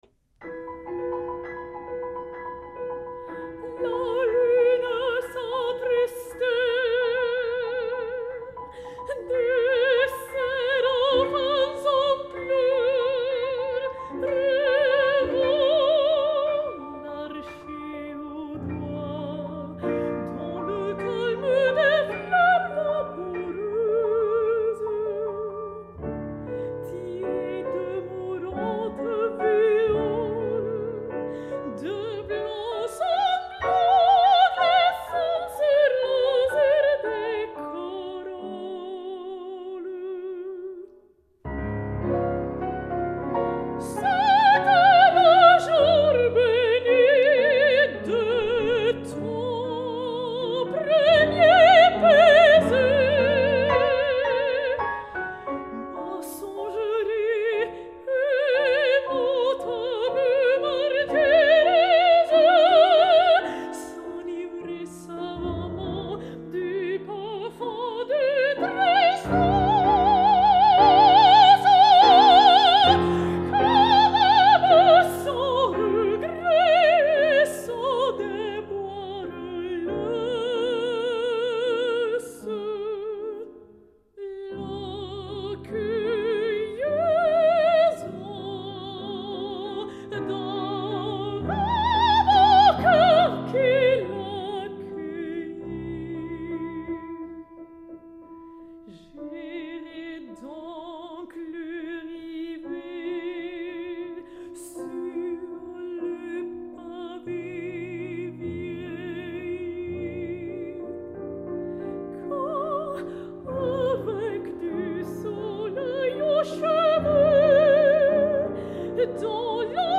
Incontro con il soprano lirico